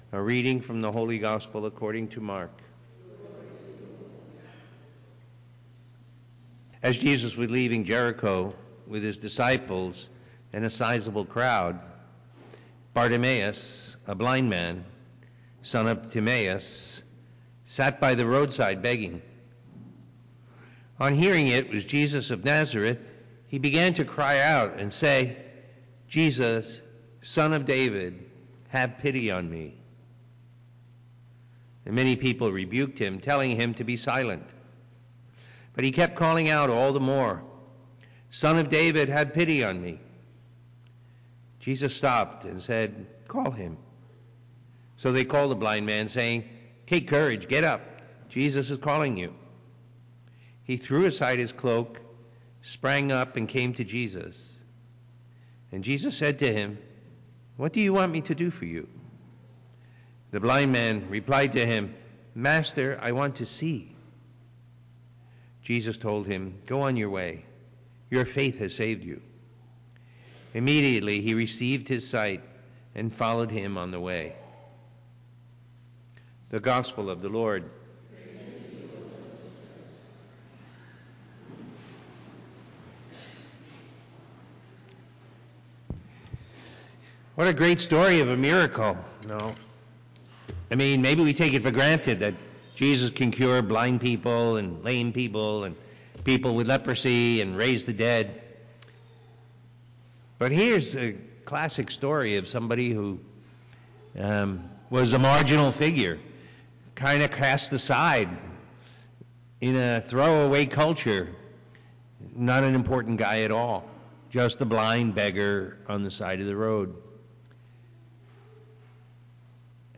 Looking to go deeper in your prayer life? Listen to the homily from the Sunday Mass and meditate on the Word of God.